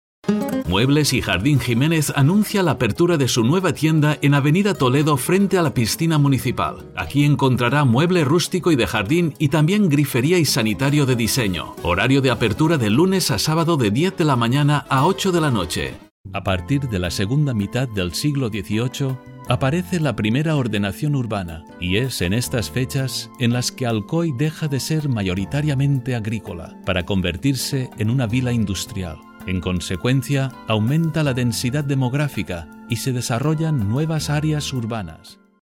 Voice-Age adult Voice-Style middle, deep Country Spanien Sex male Voice Probe Spanisch (EU)